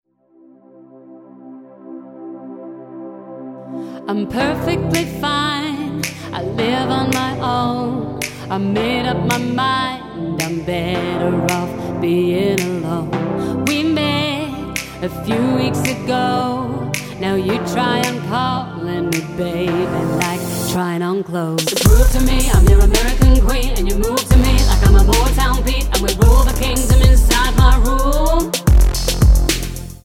Tonart:C Multifile (kein Sofortdownload.
Die besten Playbacks Instrumentals und Karaoke Versionen .